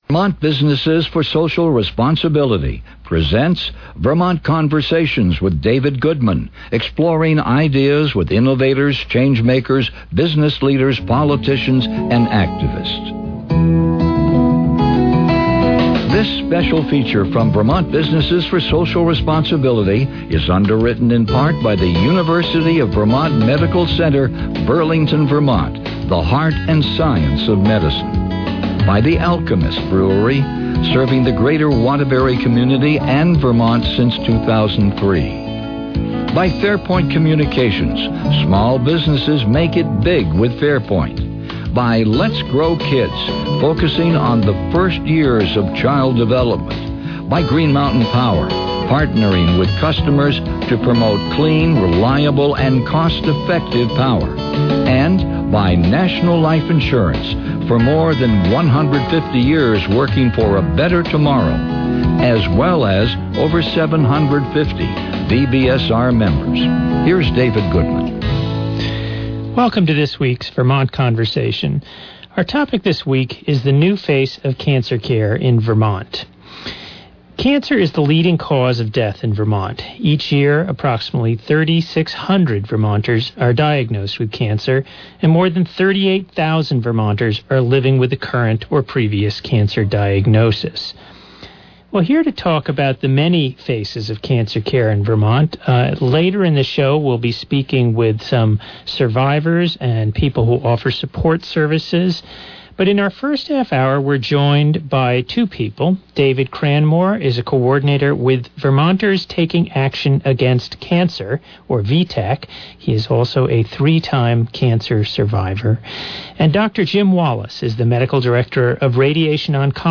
We talk with advocates, patients and physicians about the changing face of cancer care in Vermont: